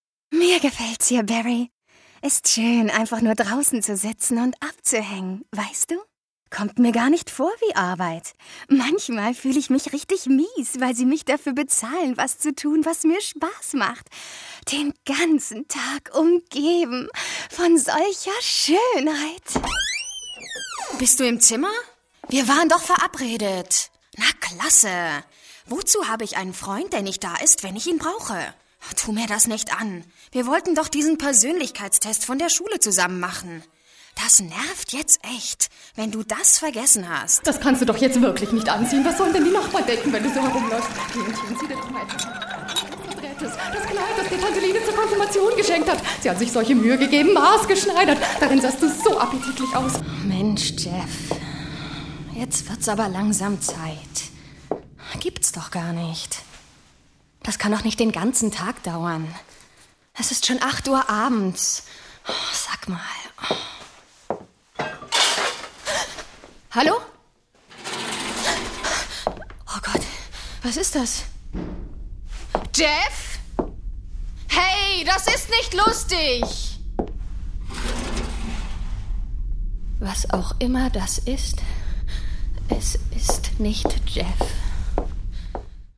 Junge bis mittelalte vielseitig einsetzbare Stimme für alle Arten von Audioproduktionen.
deutsche Sprecherin Junge bis mittelalte vielseitig einsetzbare Stimme,
Sprechprobe: eLearning (Muttersprache):
german female voice over artist